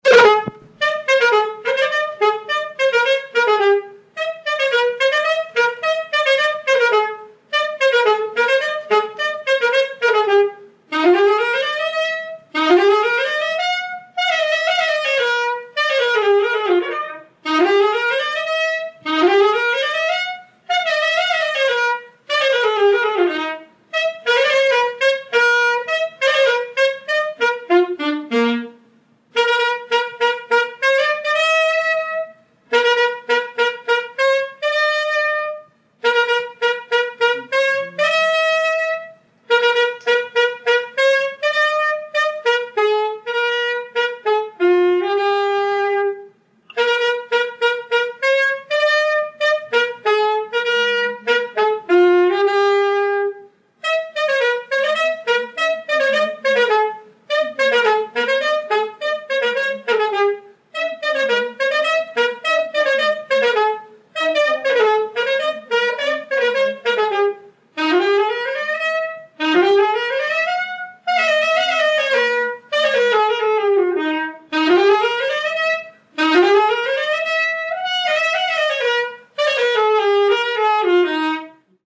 Peza de saxofón 7
Palabras chave: instrumental
Tipo de rexistro: Musical
Áreas de coñecemento: FESTAS
Soporte orixinal: Casete
Instrumentos: Saxofón